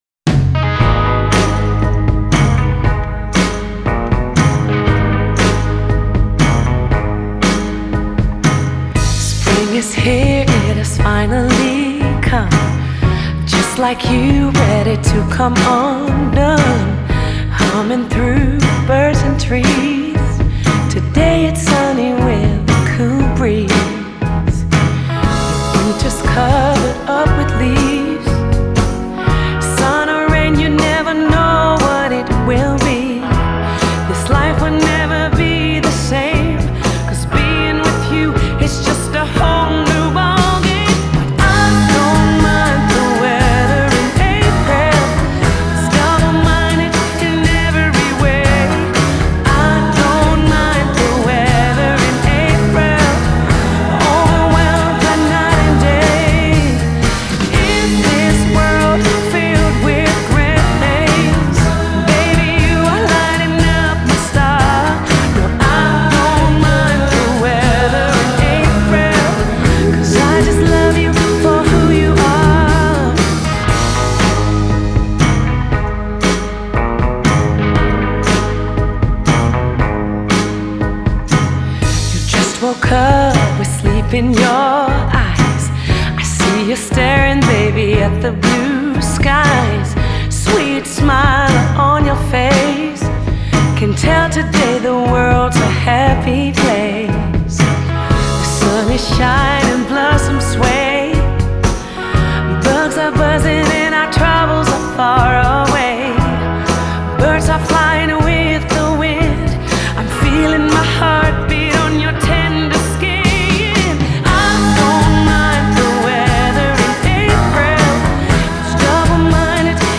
Genre: Pop / Soul / Jazz